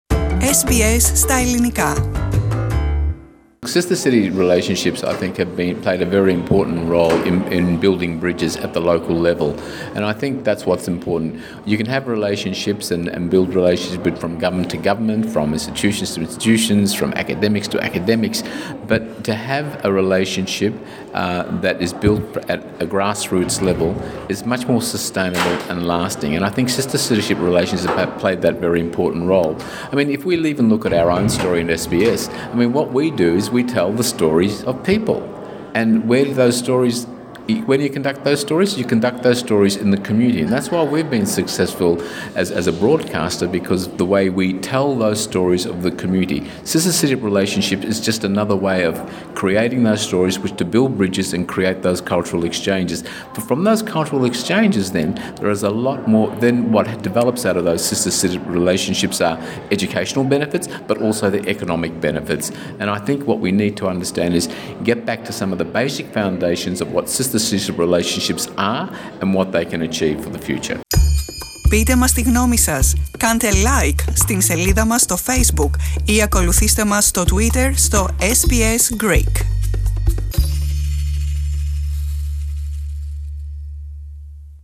at the Victorian Sister Cities Forum, at the Parliament of Victoria, Melbourne